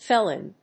/ˈfɛlɪn(米国英語), ˈfelɪn(英国英語)/